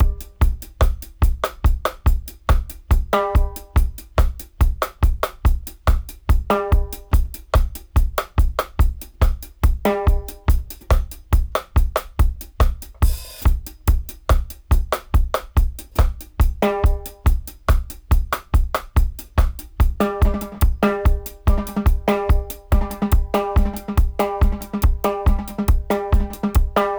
142-DRY-04.wav